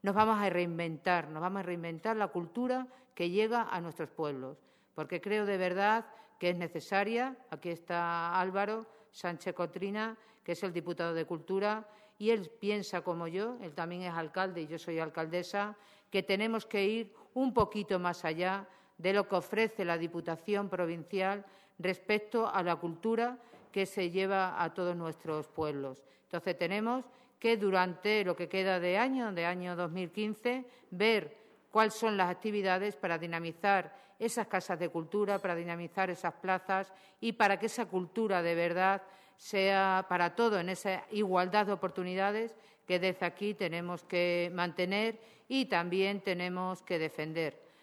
CORTES DE VOZ
23/07/2015-. La presidenta de la Diputación de Cáceres, Charo Cordero, y el diputado de Cultura, Álvaro Sánchez Cotrina, han presentado hoy uno de los eventos más veteranos de la programación de la Institución Cultural el Brocense: ‘Estivalia’, que este año cumple ya su XXVII edición y que se va a celebrar del 1 al 29 de agosto en 16 municipios de la provincia de Cáceres.